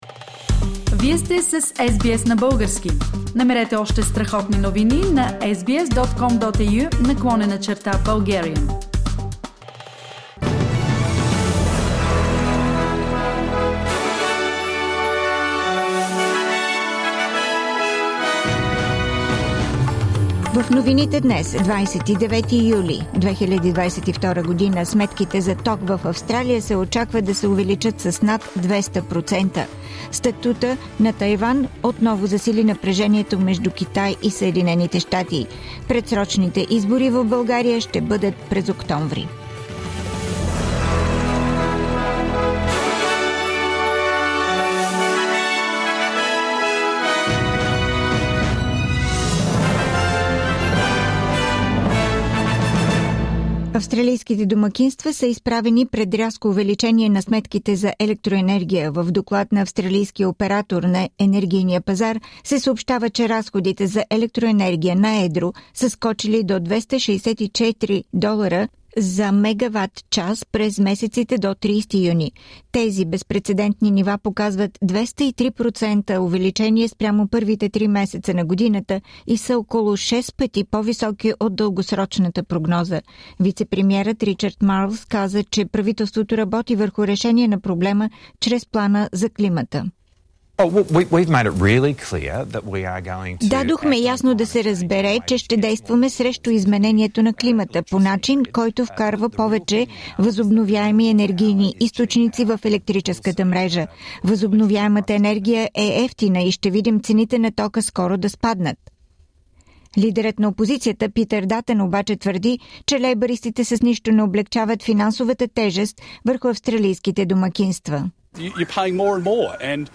Weekly Bulgarian News – 29nd July 2022